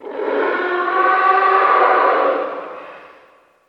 Здесь собраны уникальные аудиозаписи, воссоздающие атмосферу древних гробниц: от приглушенных стонов до зловещего шелеста бинтов.
Звук рычания мумии из соседней комнаты